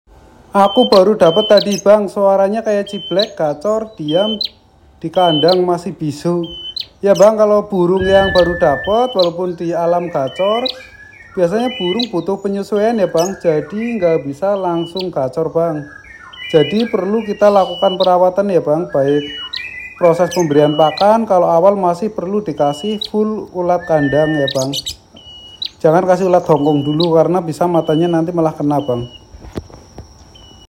Burung Prenjak